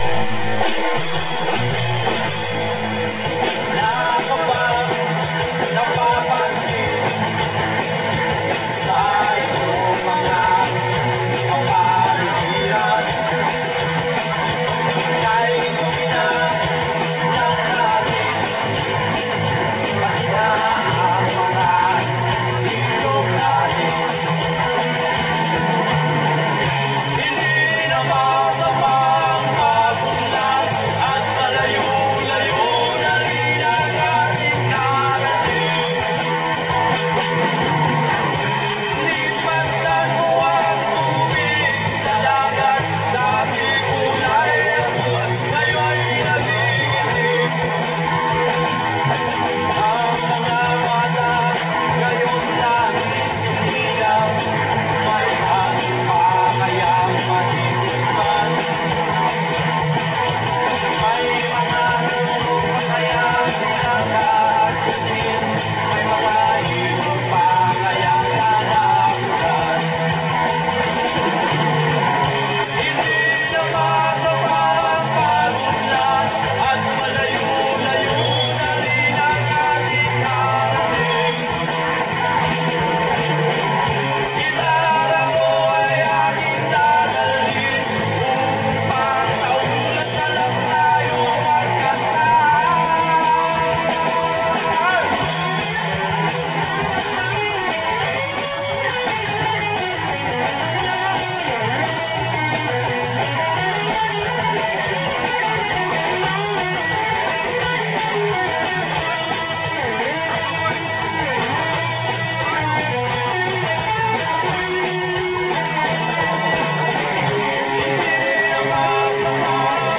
music jam